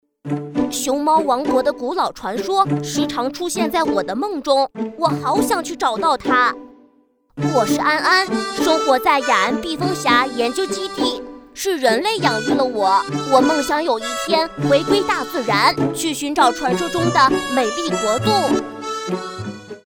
仿男童-女16-男童熊猫.mp3